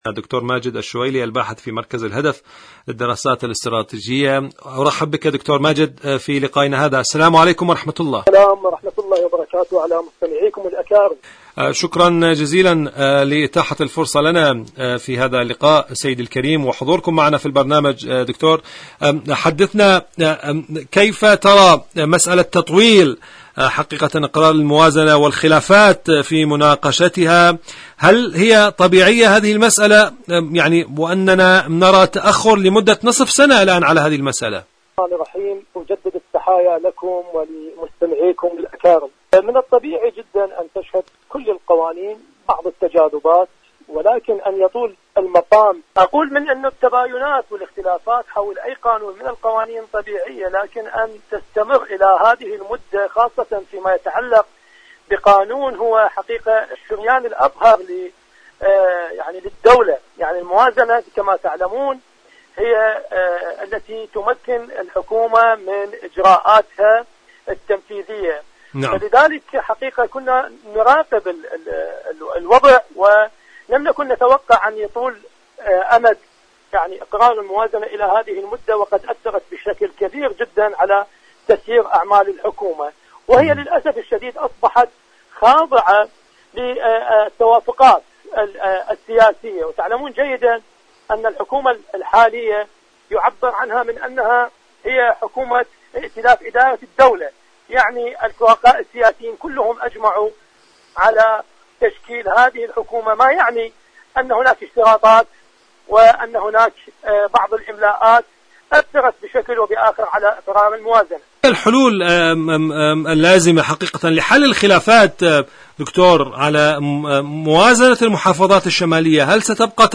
إذاعة طهران-عراق الرافدين: مقابلة إذاعية